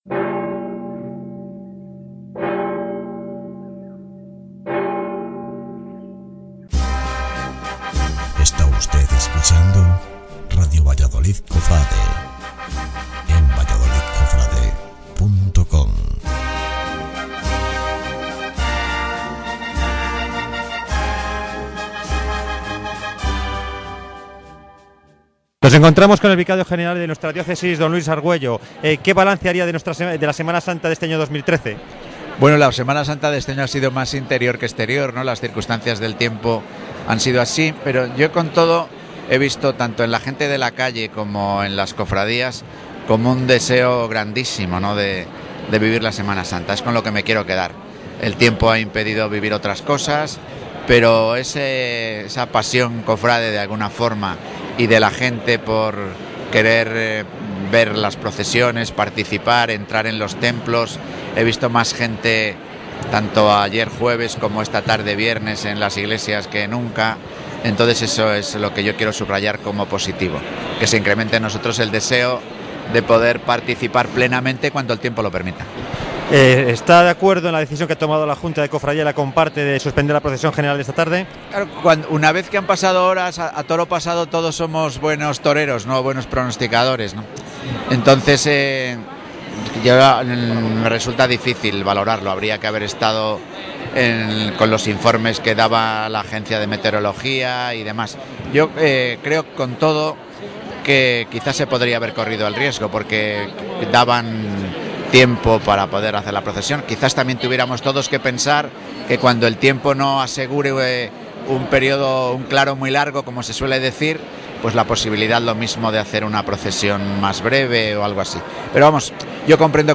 Entrevista al Vicario General de la Diócesis D.Luis Argüello
entrevistavicario.mp3